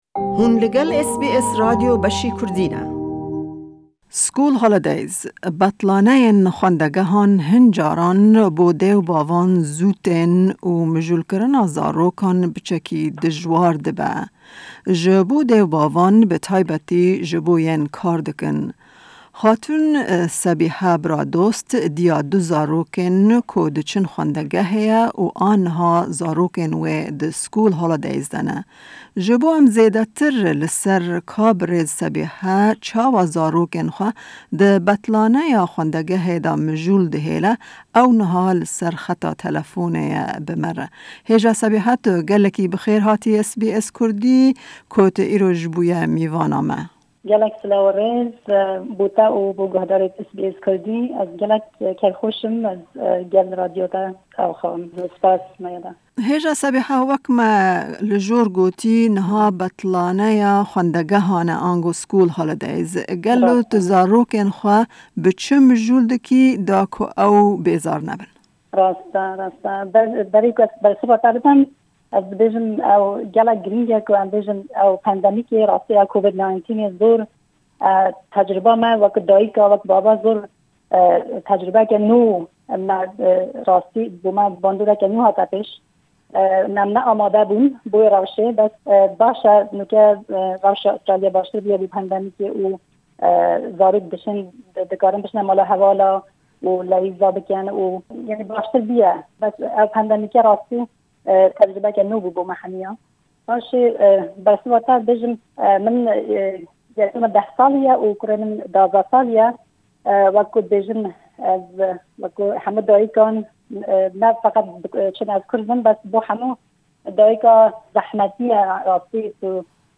hevpeyvînê